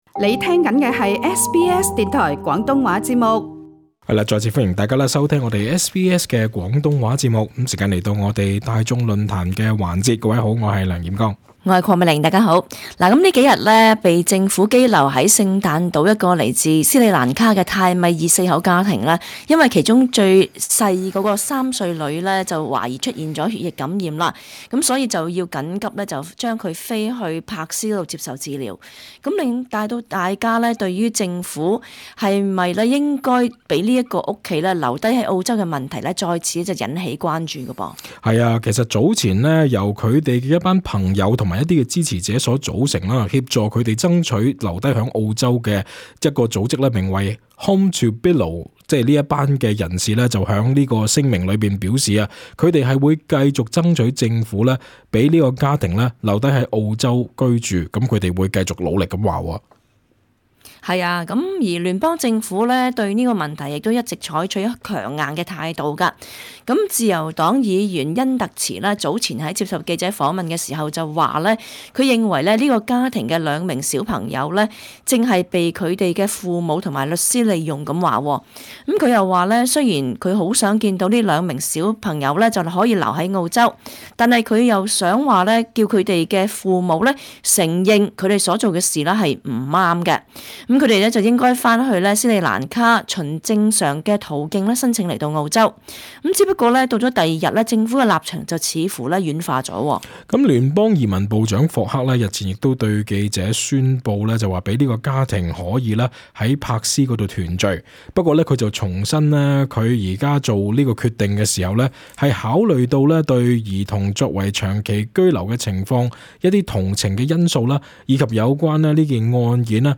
】與聽眾傾談。 本節目內的嘉賓及聽眾意見並不代表本台立場.